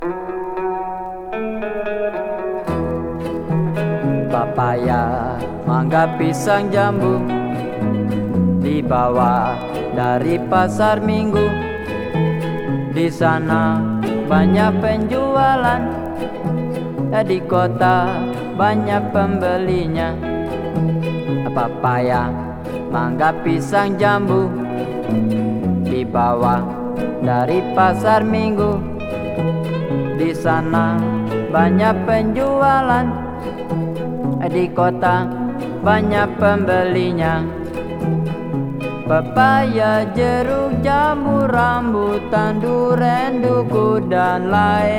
World, Folk, Krontjong　Netherlands　12inchレコード　33rpm　Stereo